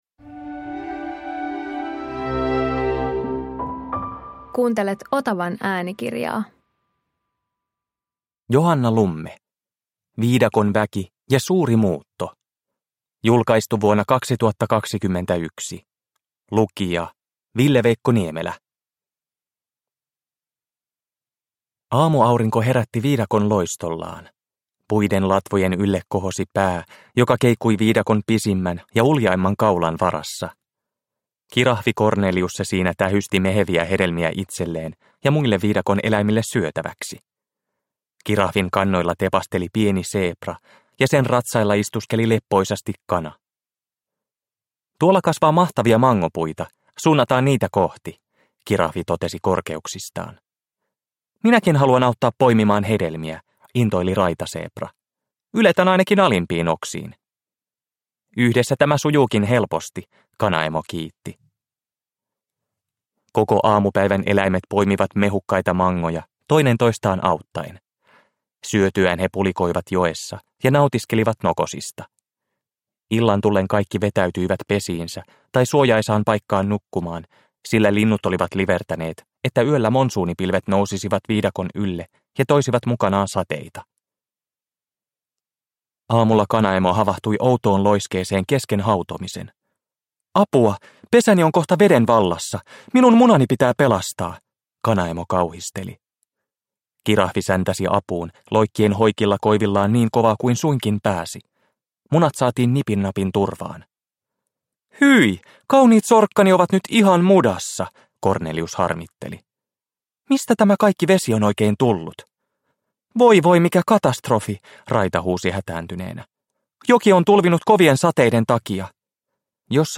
Viidakon väki ja suuri muutto – Ljudbok – Laddas ner